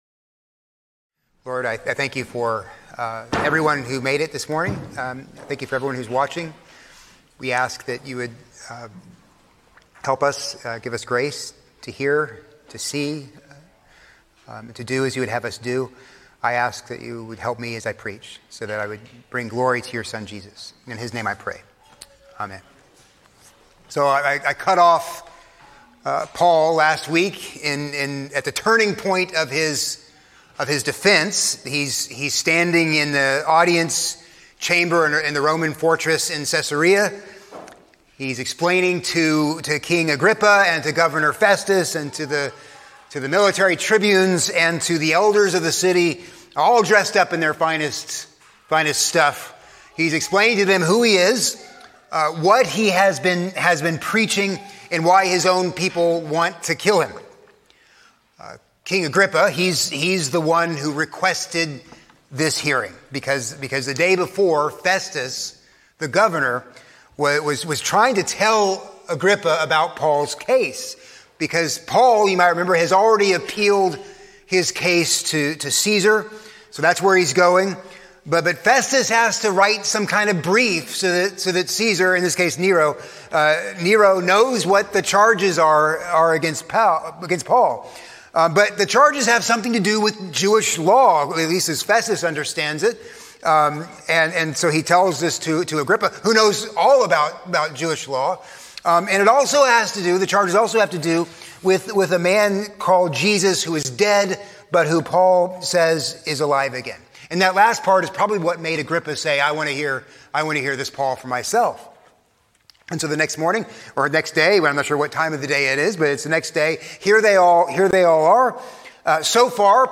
A sermon on Acts 26:12-23